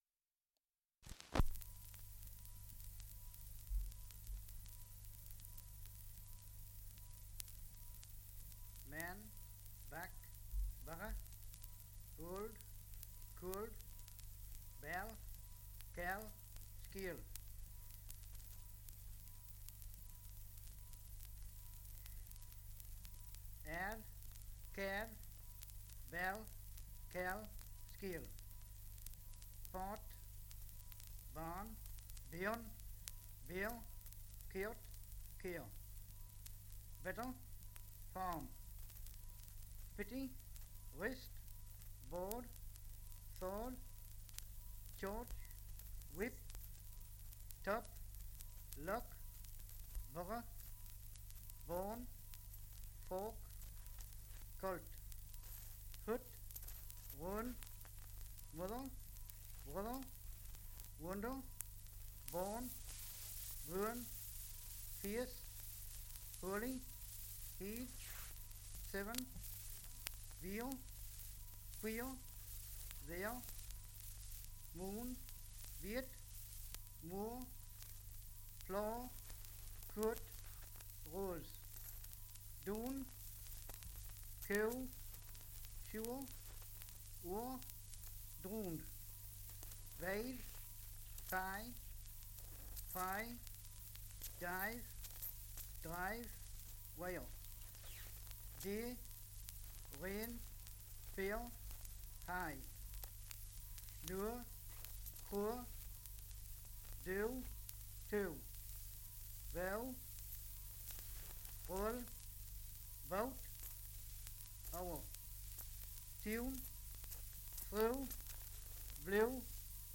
Dialect recording in Ovingham, Northumberland
78 r.p.m., cellulose nitrate on aluminium
English Language - Dialects